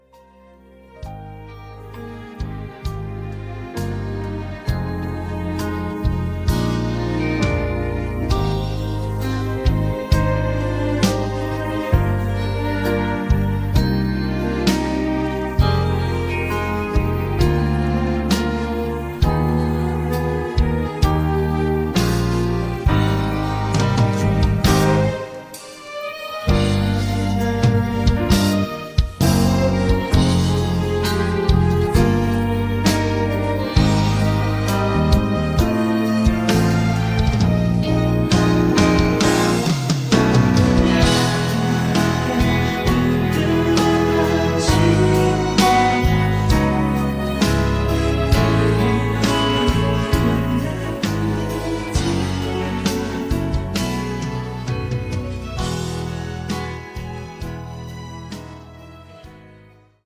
음정 -1키 5:37
장르 가요 구분 Voice MR